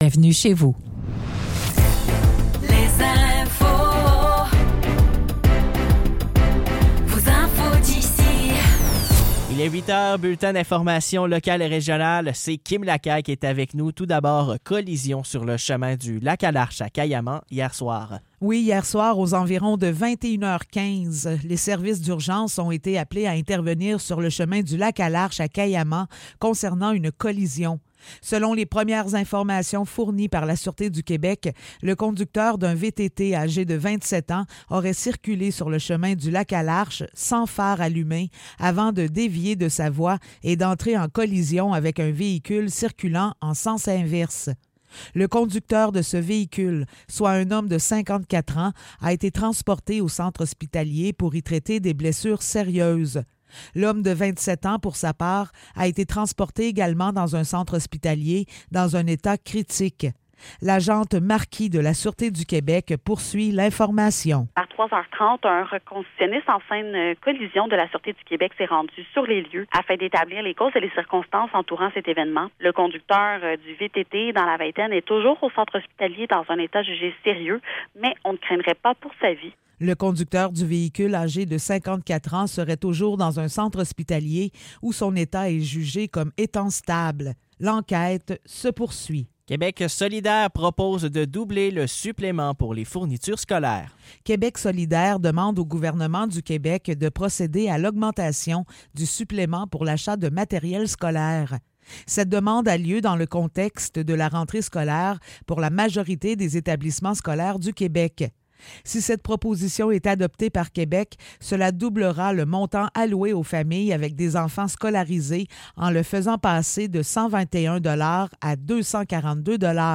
Nouvelles locales - 23 Août 2024 - 8 h